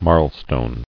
[marl·stone]